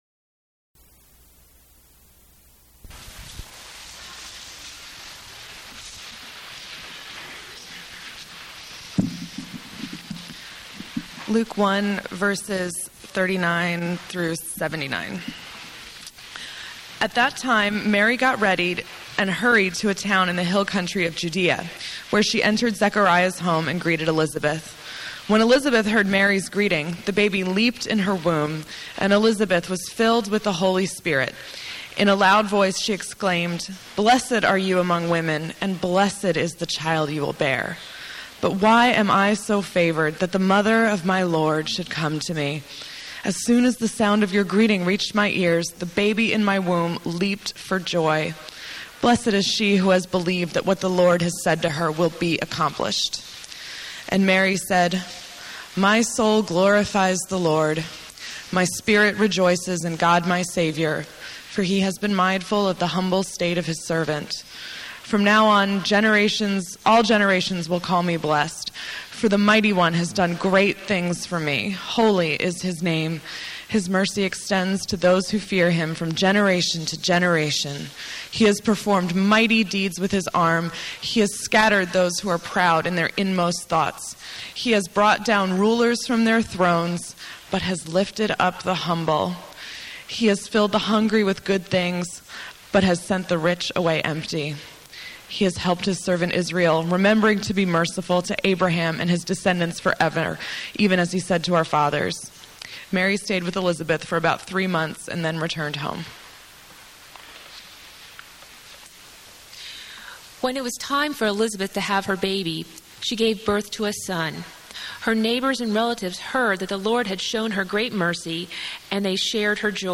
I reproduce below the Rupert Brooke poem I quote from in the sermon, along with the sermon recording.